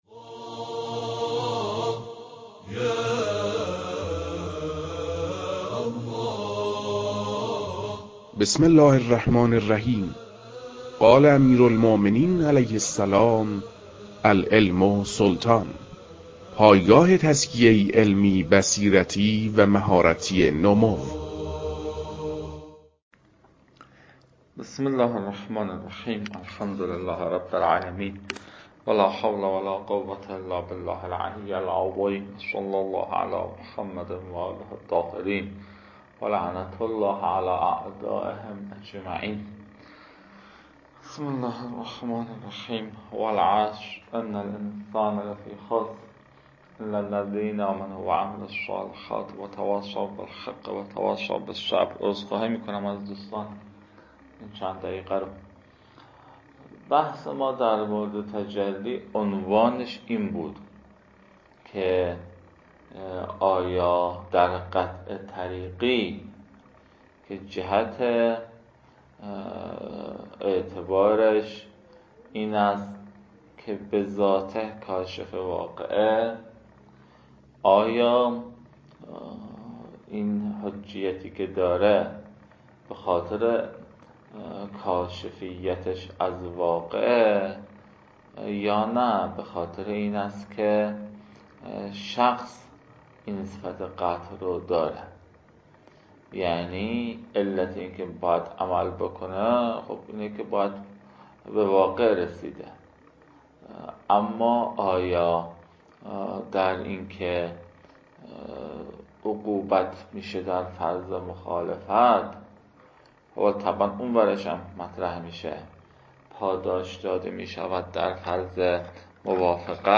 فایل های مربوط به تدریس مبحث رسالة في القطع از كتاب فرائد الاصول